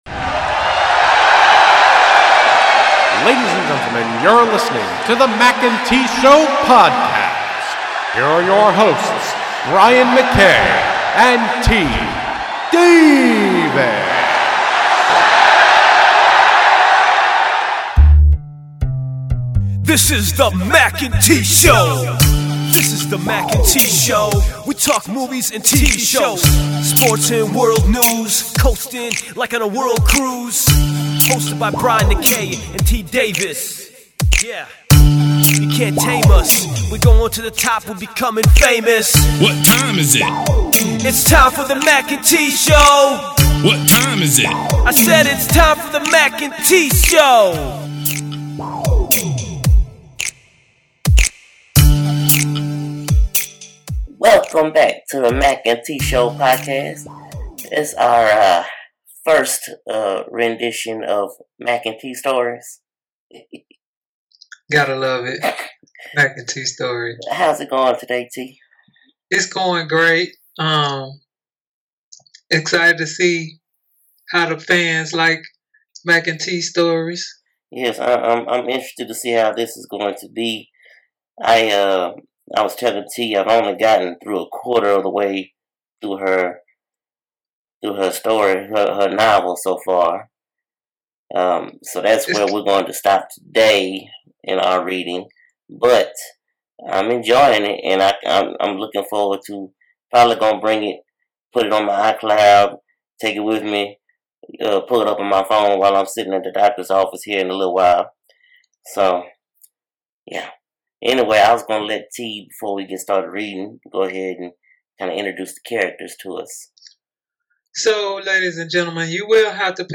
It's story time with your favorite podcast duo!